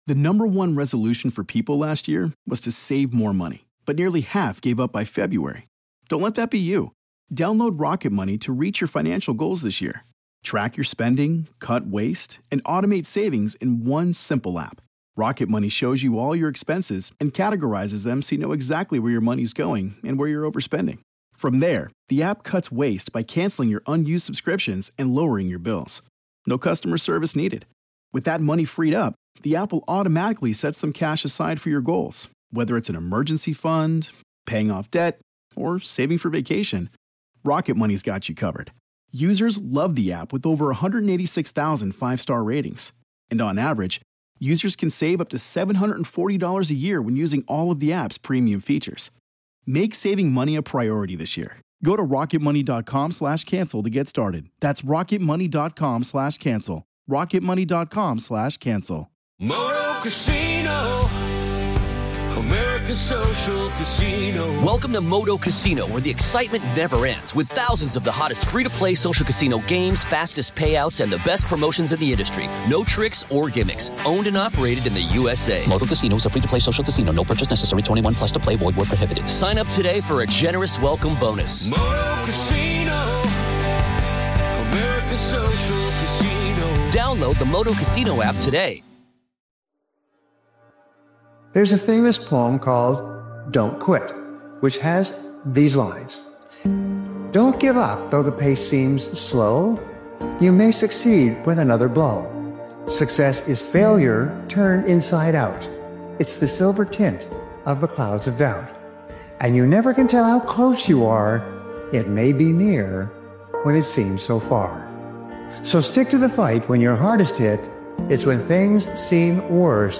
Powerful Motivational Speech Video is a raw and self-accountable motivational video created and edited by Daily Motivations. This powerful motivational speeches compilation delivers the hard truth most people avoid—your environment won’t save you, timing won’t save you, and excuses won’t save you.